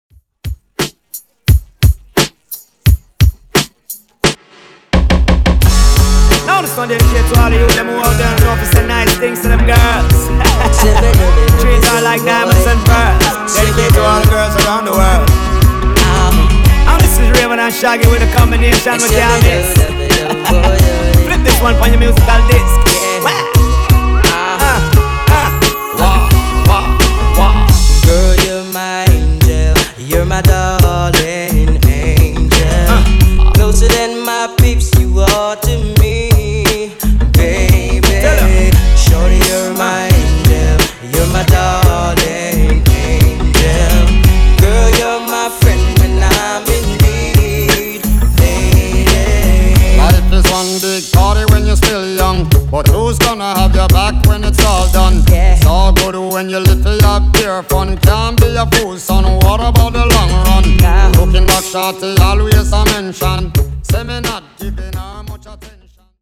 Genre: RE-DRUM Version: Clean BPM: 87 Time